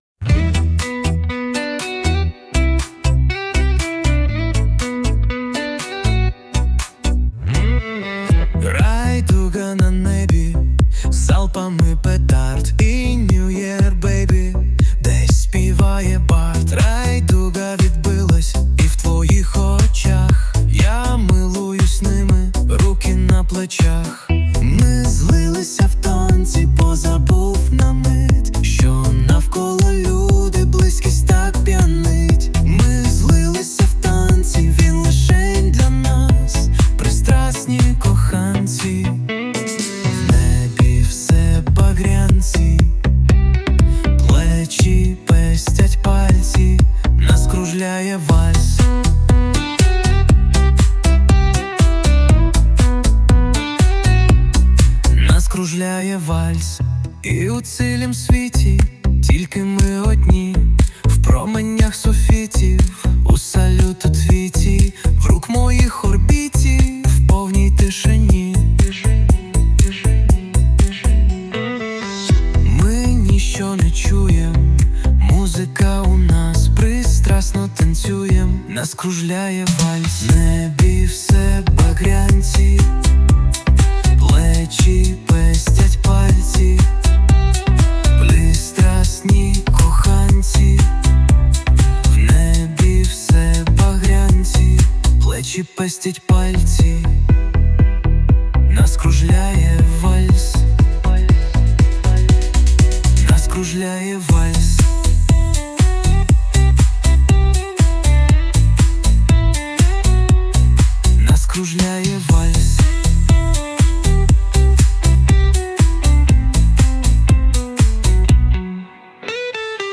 ТИП: Пісня
СТИЛЬОВІ ЖАНРИ: Ліричний
12 12 12 Прекрасний текст (музика вальсу трохи не відповідає ритму, у вальсі розмір 3/4)